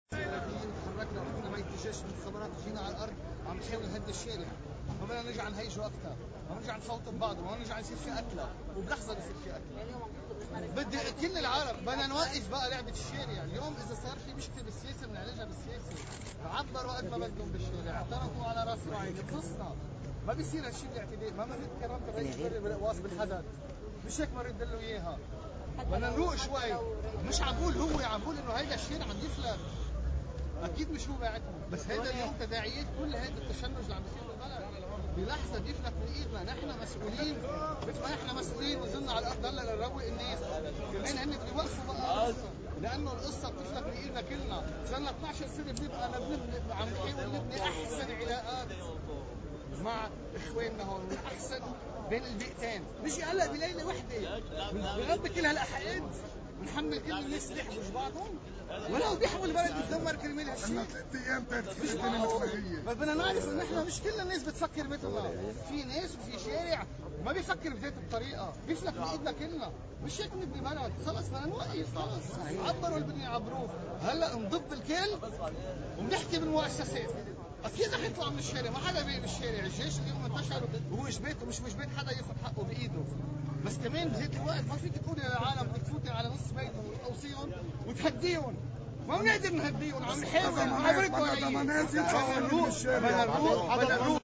كلمة عضو تكتّل التغيير والإصلاح النائب الان عون من منطقة “الحدث”: (31- ك2 – 2018)